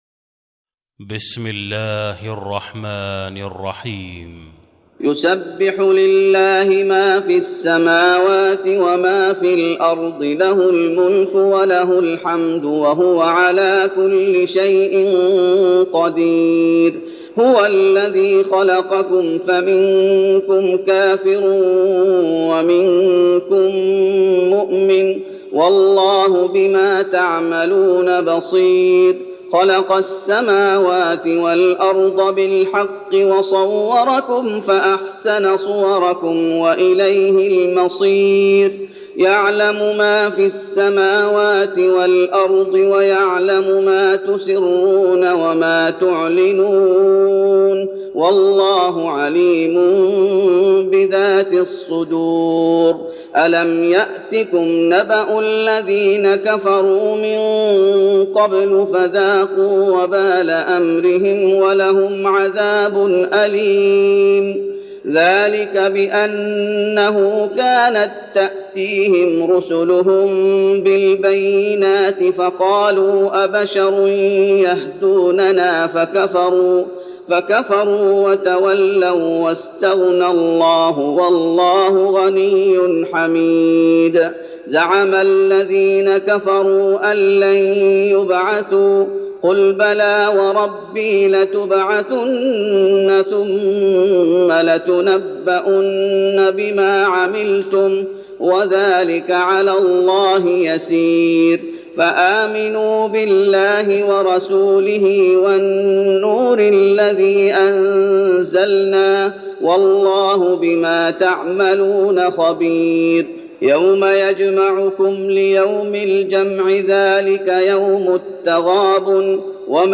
تراويح رمضان 1412هـ من سورة التغابن Taraweeh Ramadan 1412H from Surah At-Taghaabun > تراويح الشيخ محمد أيوب بالنبوي 1412 🕌 > التراويح - تلاوات الحرمين